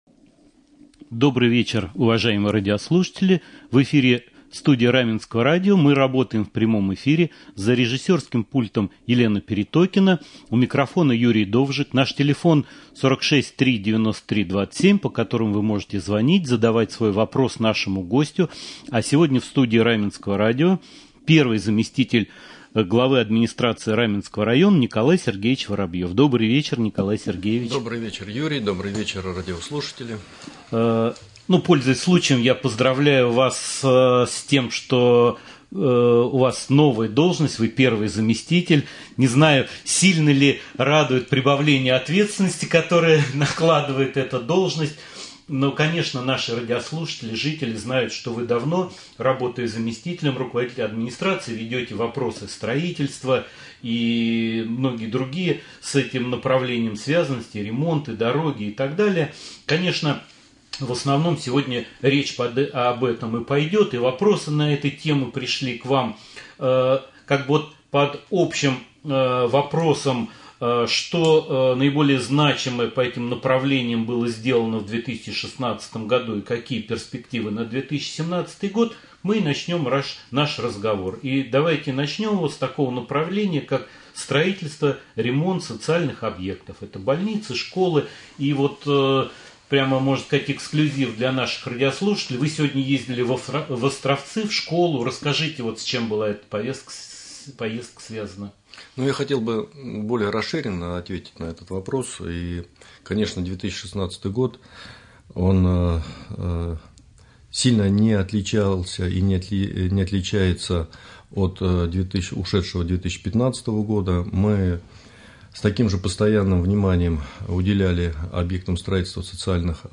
Прямой эфир. Гость студии первый заместитель Главы администрации Раменского района Н.С.Воробьев.
Николай Воробьев в прямом эфире Раменского радио